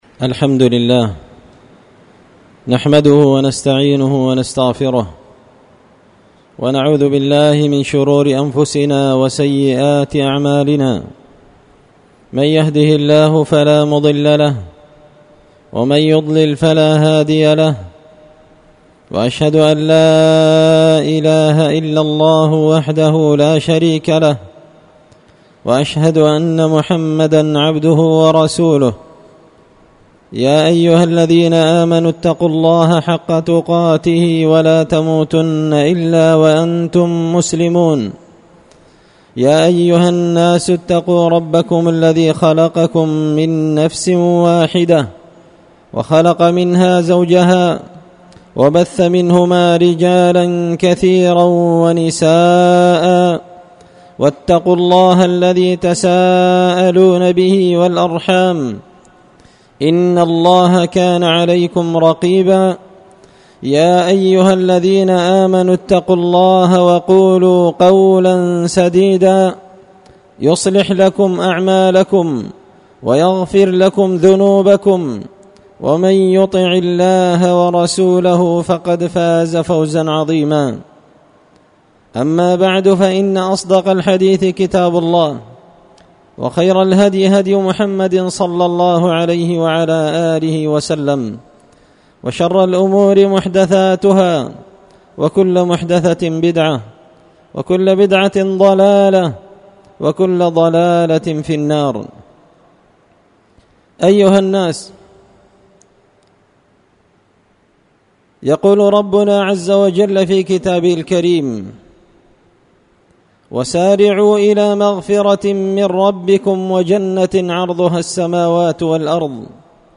خطبة جمعة بعنوان – الذين لايريحون رائحة الجنة
دار الحديث بمسجد الفرقان ـ قشن ـ المهرة ـ اليمن